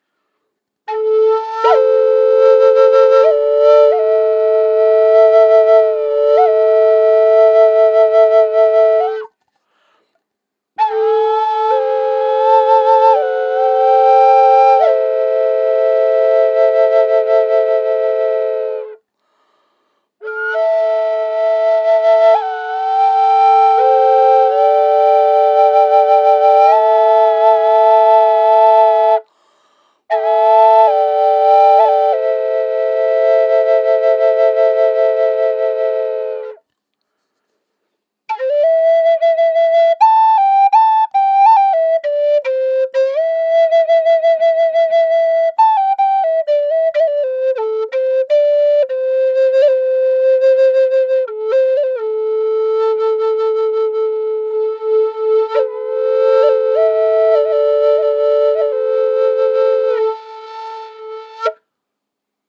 Korkeahko ja lempeä sointitaajuus. Viritetty pentatoniseen molli sävelasteikkoon.
• Tyyli: Triple drone
Ääninäyte ilman efektejä (dry):
A4_440hz_tripledrone_pentatoninenmolli_DRY.mp3